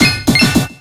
Audio / SE / Cries / BRONZOR.ogg